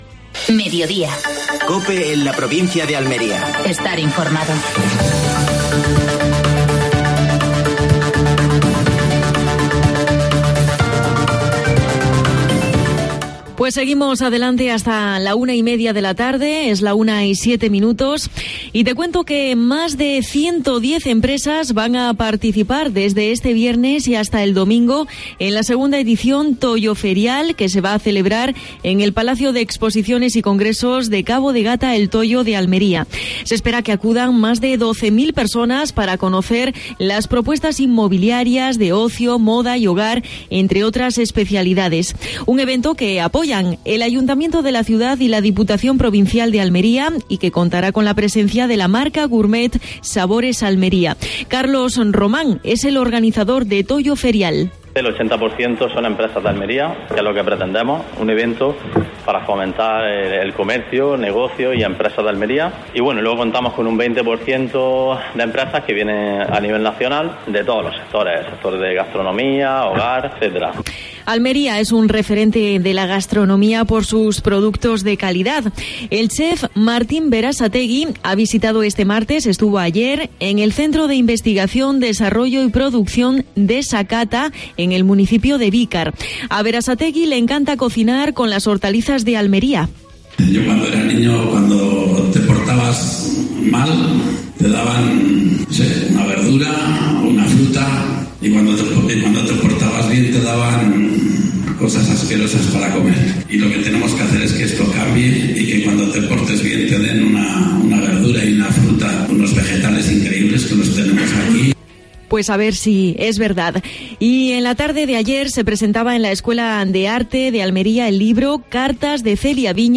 AUDIO: Actualidad en Almería. Entrevista a Diana Navarro que este sábado actúa en Adra. Sección de la Guardia Civil.